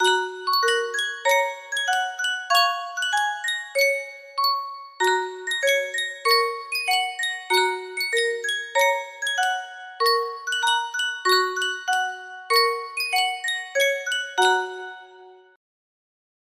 Sankyo Music Box - 金太郎 田村虎蔵 BBX
Full range 60